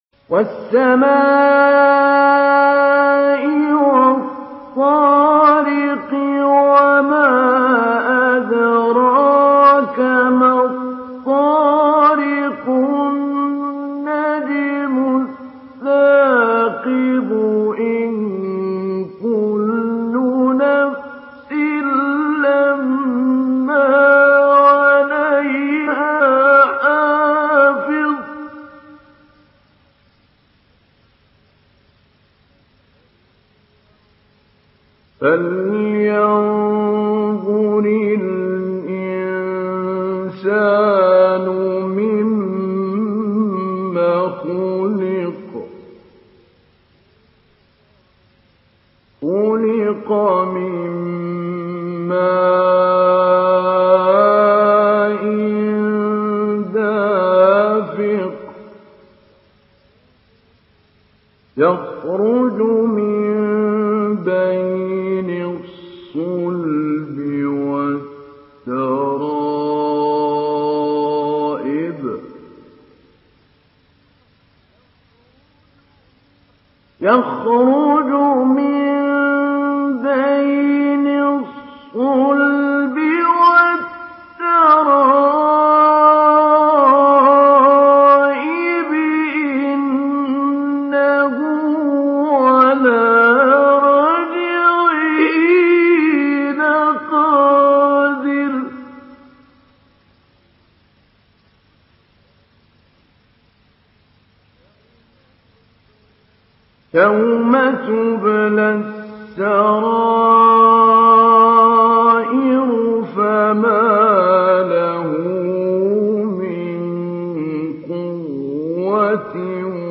Une récitation touchante et belle des versets coraniques par la narration Hafs An Asim.
Mujawwad